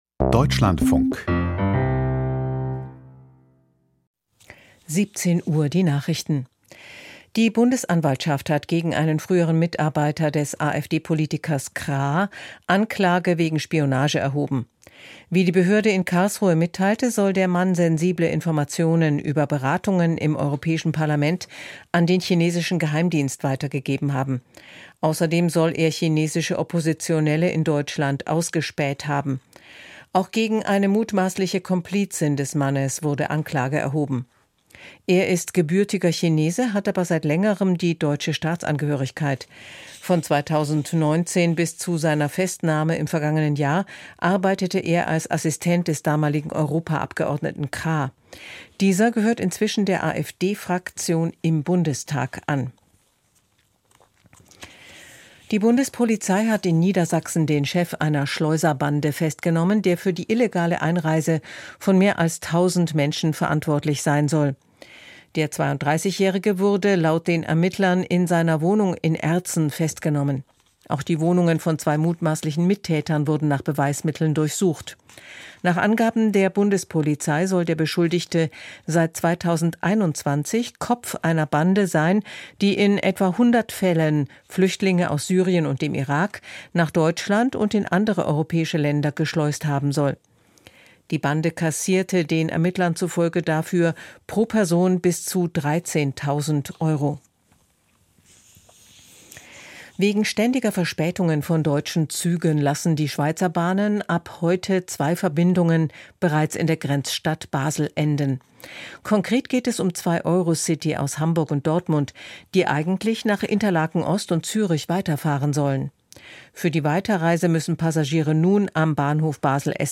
Die Deutschlandfunk-Nachrichten vom 29.04.2025, 17:00 Uhr